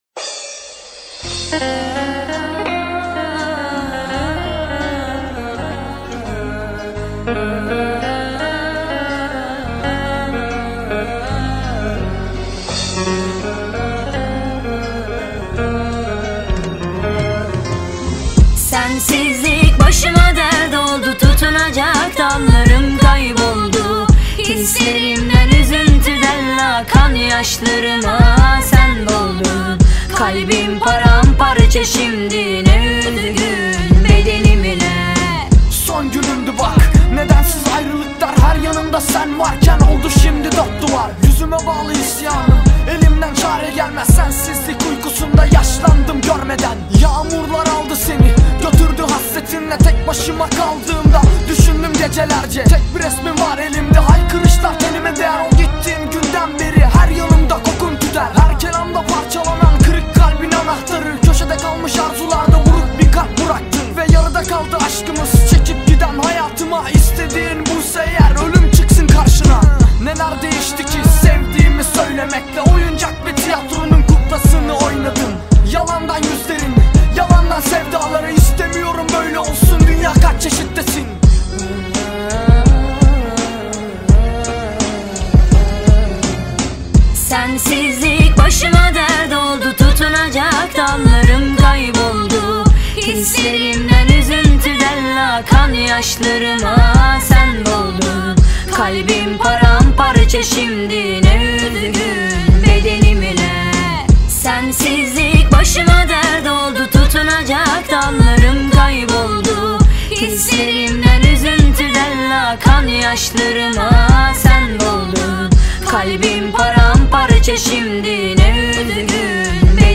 Yeni musiqilər - rap